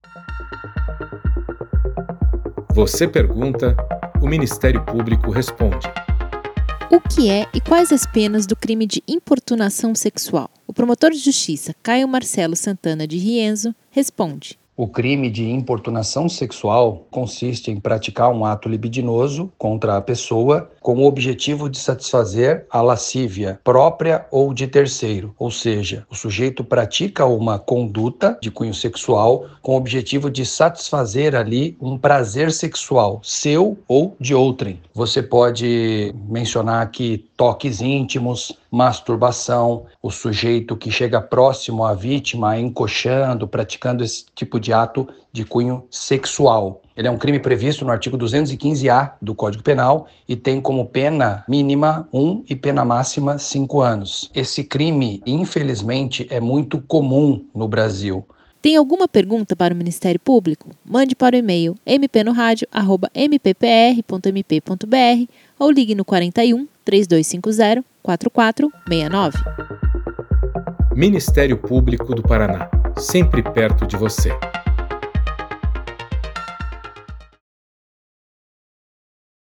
O MP Responde esclarece dúvidas sobre importunação sexual, respondidas pelo promotor de Justiça Caio Marcelo Santana Di Rienzo, que foi responsável por denúncia criminal contra um motorista de van que praticou esse crime em Marechal Cândido Rondon.